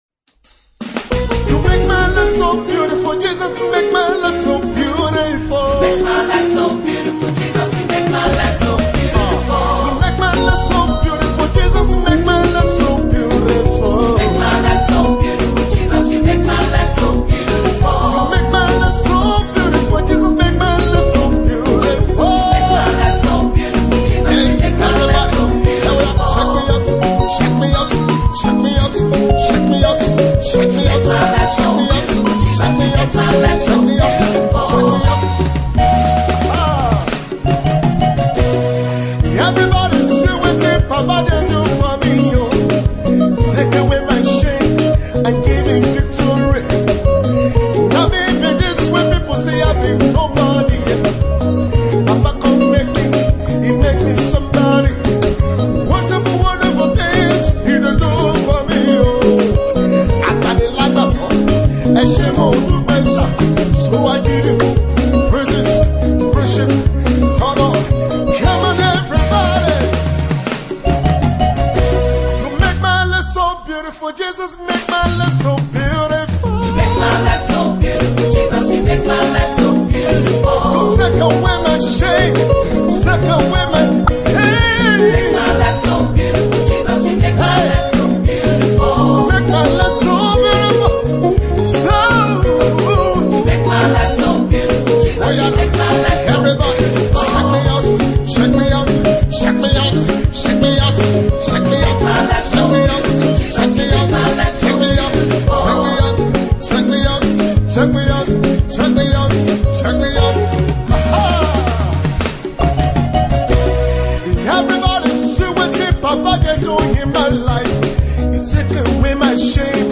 Nigerian gospel artist